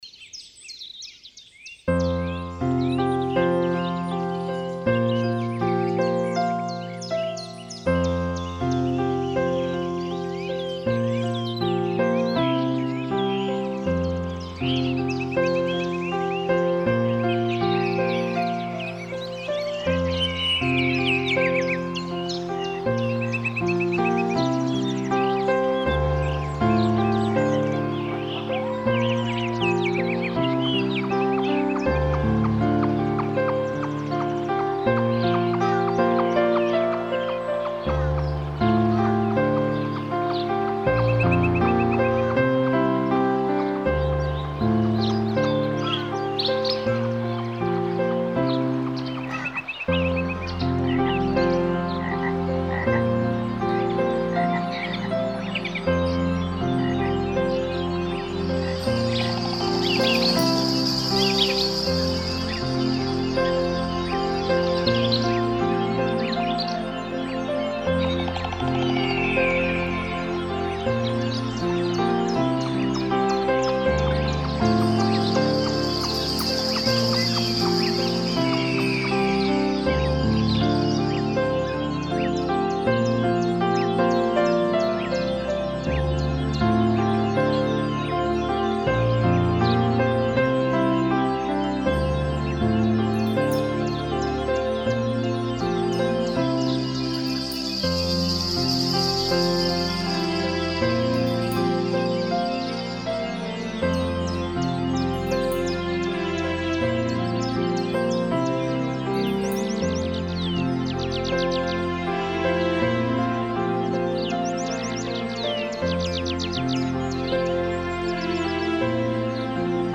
Медитативная музыка New age Нью эйдж